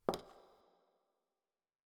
heel-reverb2.wav